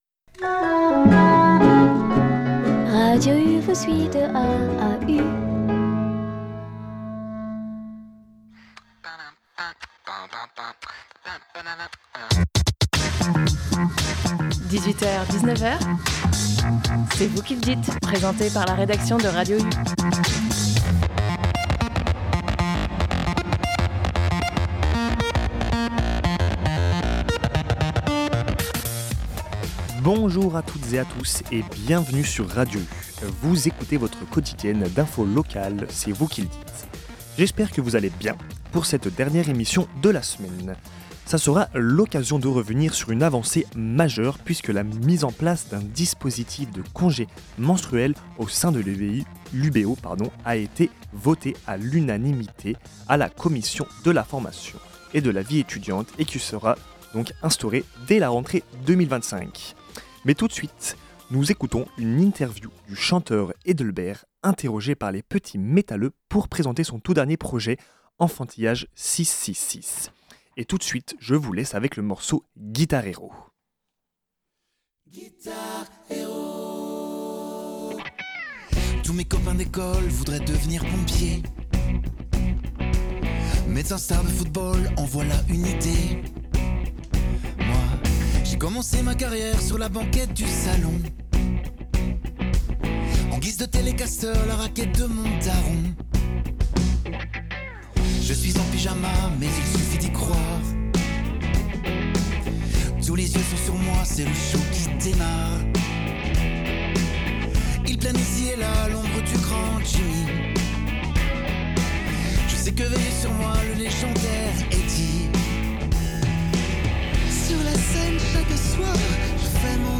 En deuxième partie d’émission : une interview réalisée par les petits metaleux du chanteur Aldebert.